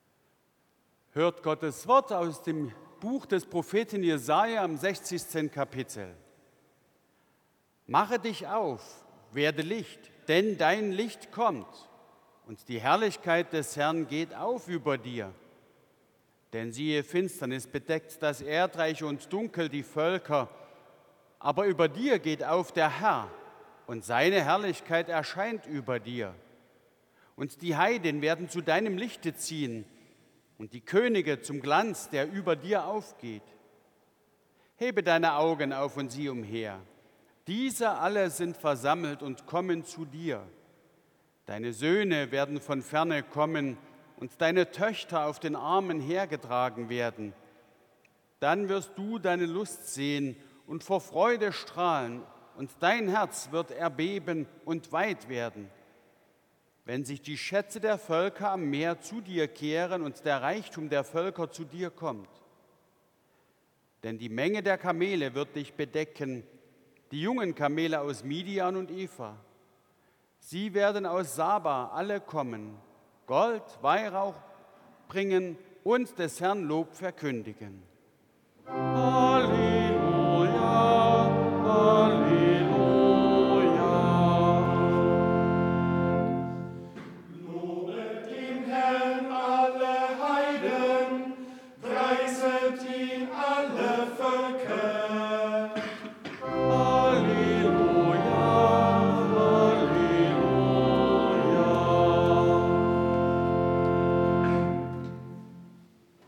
Lesung aus Jesaja 60,1-6 Ev.-Luth.
Audiomitschnitt unseres Gottesdienstes zum Epipaniasfest 2026.